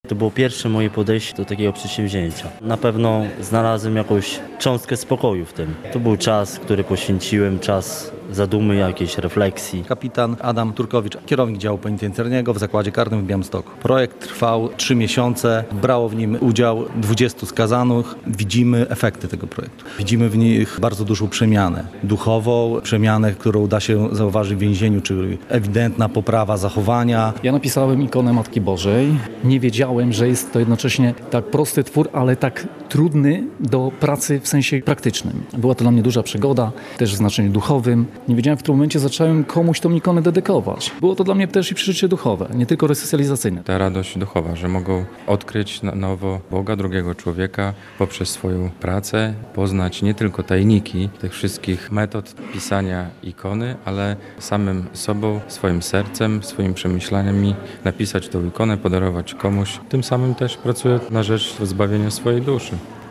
Więźniowie pisali ikony - relacja